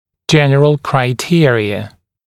[‘ʤen(ə)r(ə)l kraɪ’tɪərɪə][‘джэн(э)р(э)л край’тиэриэ]общие критерии